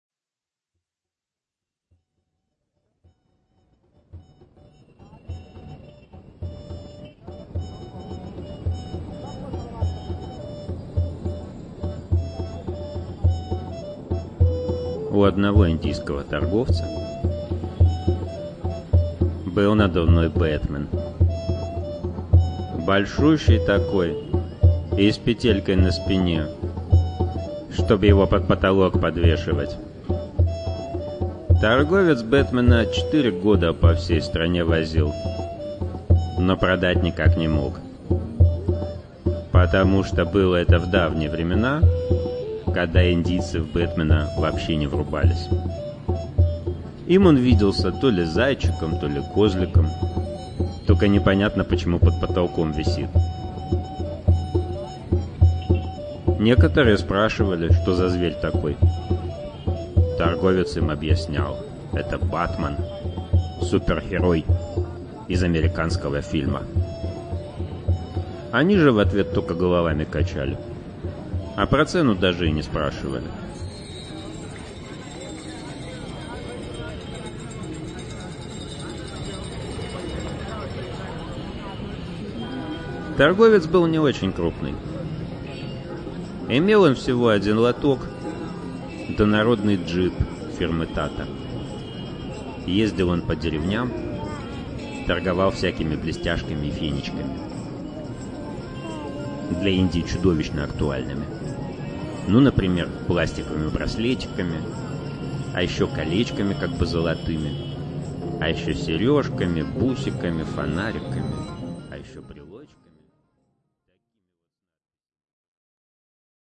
Аудиокнига Самый сильный бог | Библиотека аудиокниг